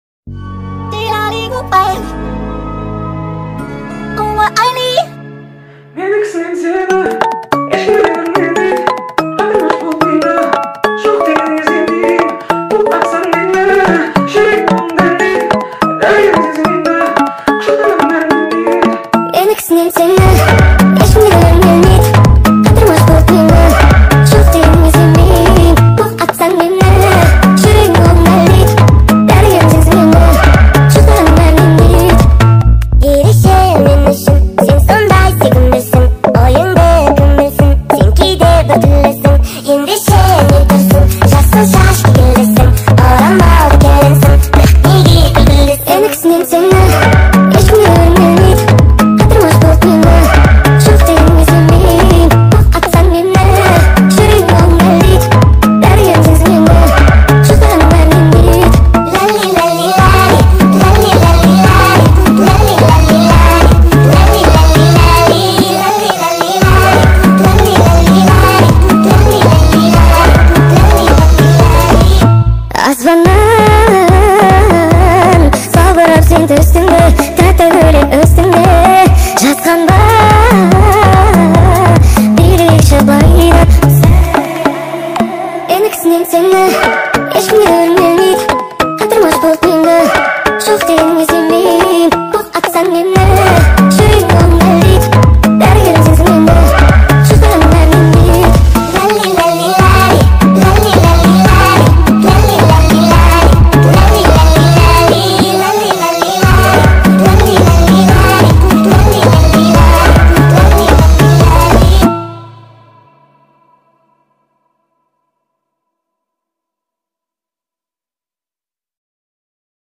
speed up version